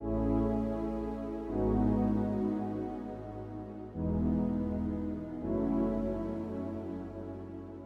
Rnb慢速垫
描述：白天晚上的慢歌
Tag: 122 bpm RnB Loops Synth Loops 1.32 MB wav Key : Unknown